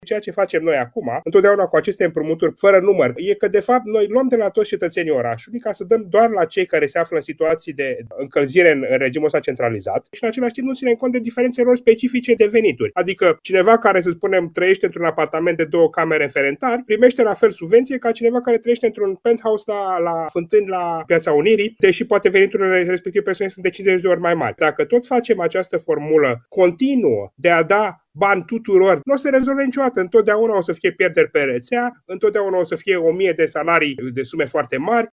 În timpul dezbaterilor, consilierul general Cătălin Teniță, de la Reper, a spus că municipalitatea ar trebui să ia în calcul ca subvenția pentru căldură să nu mai fie acordată tuturor bucureștenilor.